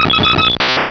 Cri de Dodrio dans Pokémon Rubis et Saphir.